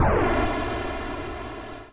snd_respawn.wav